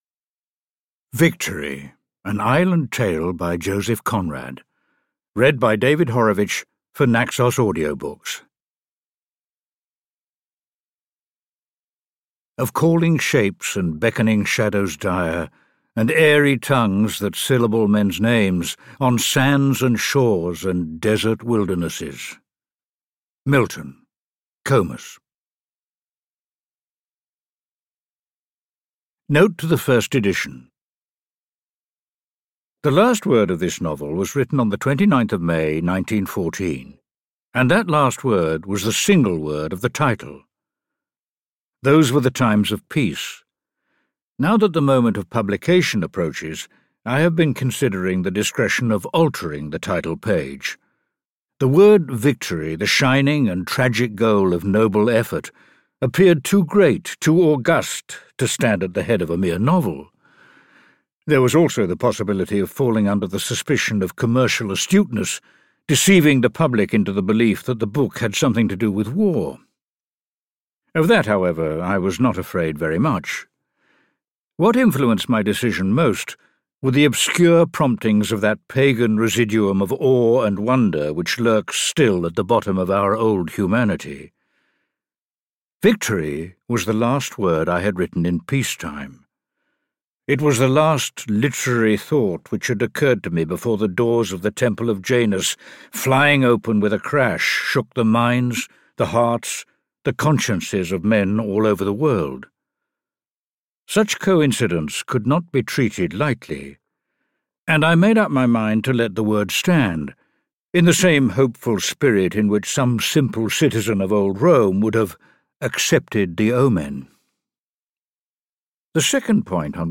Victory audiokniha
Ukázka z knihy
• InterpretDavid Horovitch